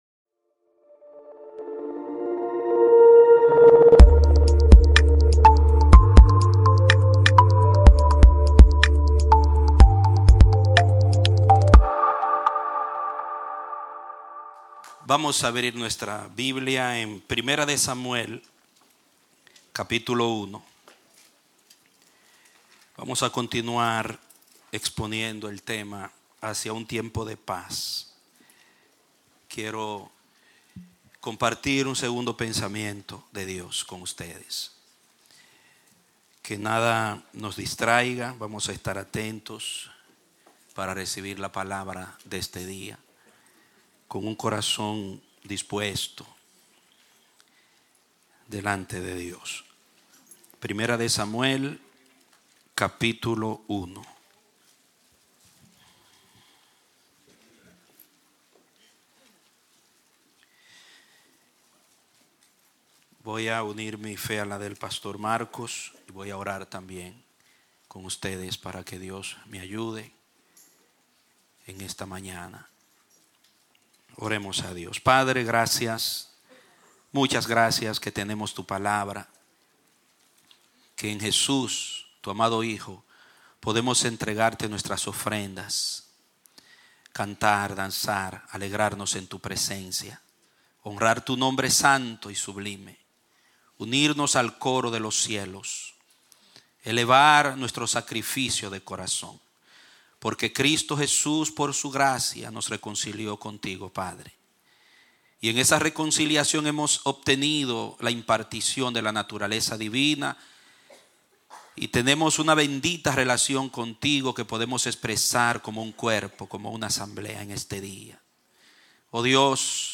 Un mensaje de la serie "Hacia un Tiempo de Paz."